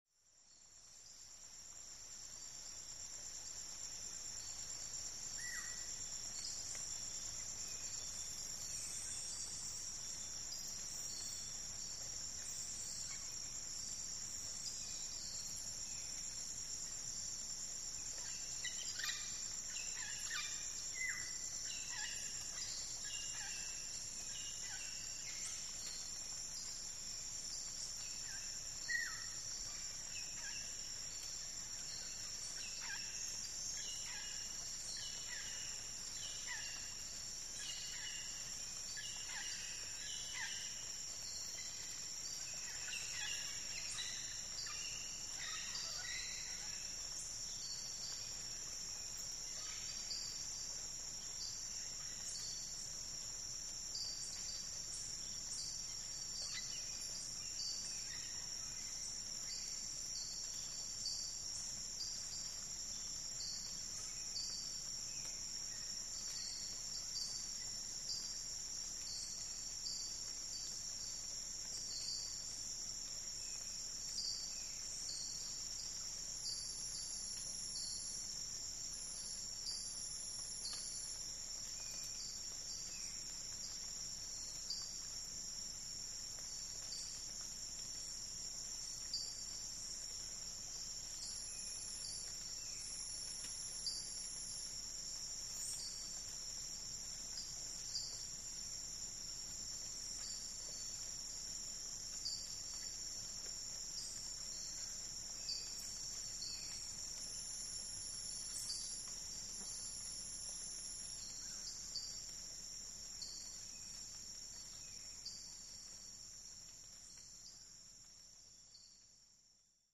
Exotic Bird Calls With Light Rain On Foliage And Cricket Bed.